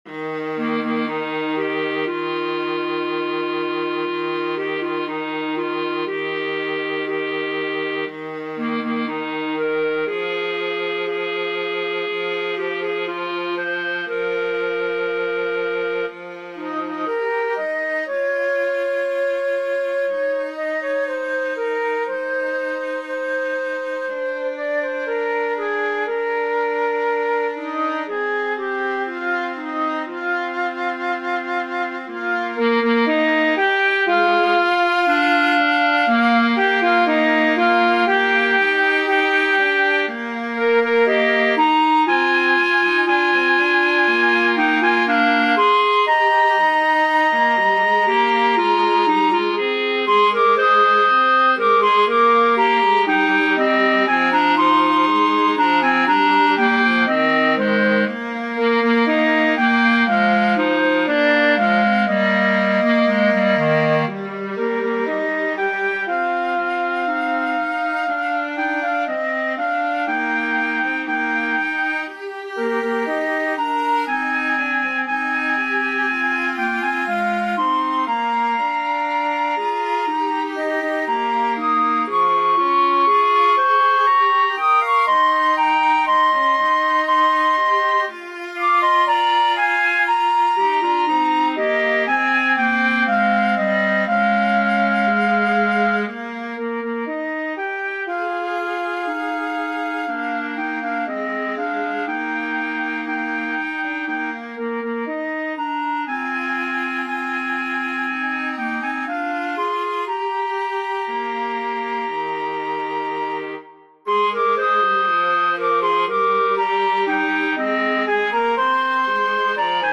Flute,Clarinet,Viola